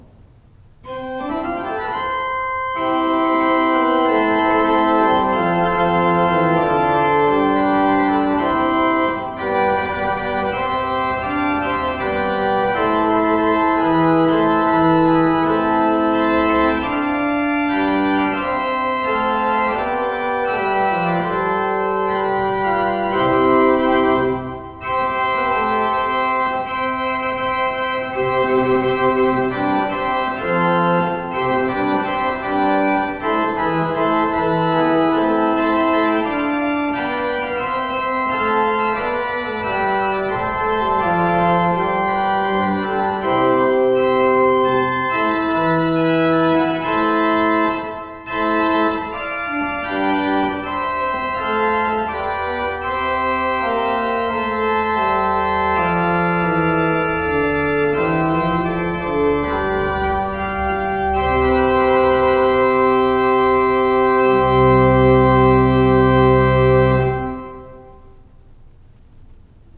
Listen to an improvisation on the Gedackt 8', Principal 4', Quinte 3' and Octav 2' by clicking